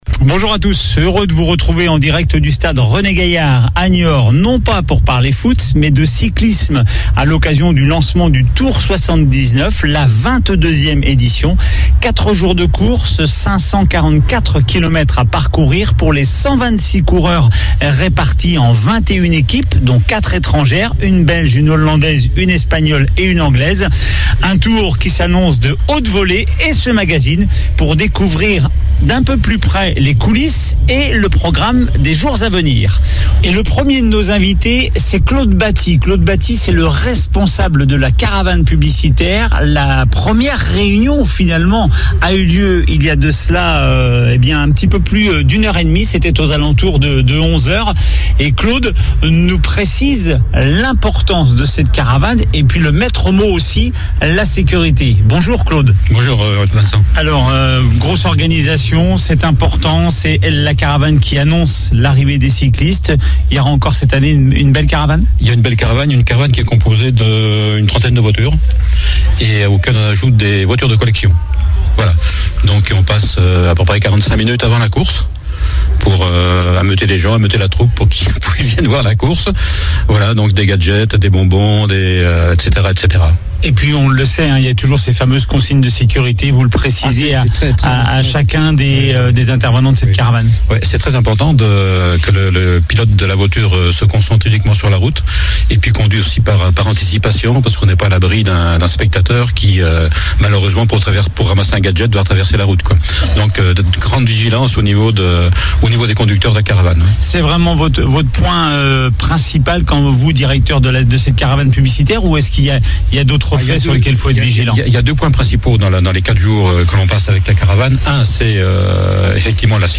en direct de Niort